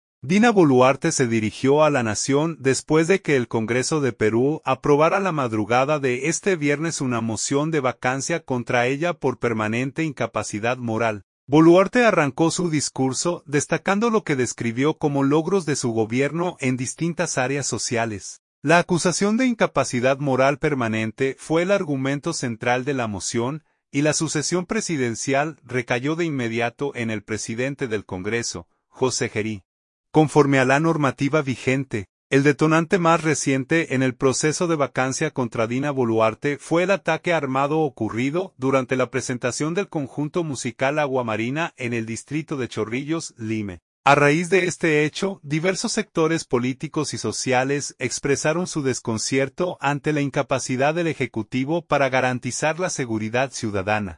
Dina Boluarte se dirigió a la nación después de que el Congreso de Perú aprobara la madrugada de este viernes una moción de vacancia contra ella por permanente incapacidad moral.